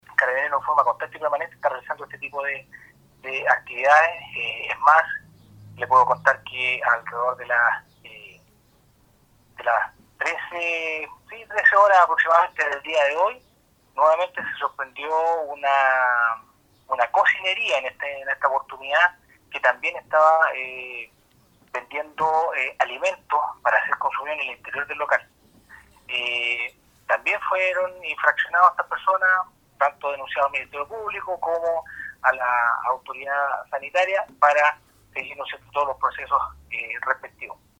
En la jornada del miércoles, un hecho similar ocurrió en local cocinería, del centro de la ciudad, donde también se pudo verificar que había clientes en su interior, consumiendo alimentos, tomándose el mismo procedimiento anterior, sostuvo el mayor de Carabineros.